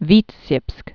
(vētsyĭpsk) or Vi·tebsk (vētĭpsk)